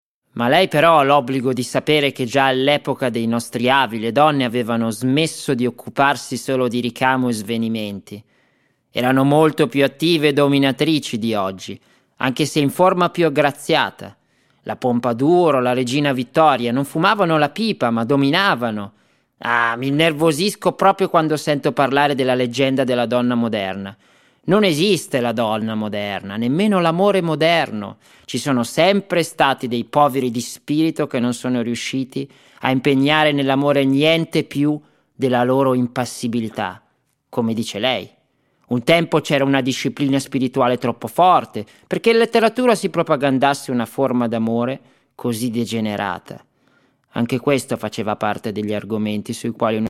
Singer, Actor, Musician & native italian, german and english native speaker active as a live music performer, professional Tv speaker and Tour Guide since 2011.
Sprechprobe: Sonstiges (Muttersprache):
Italian Character.mp3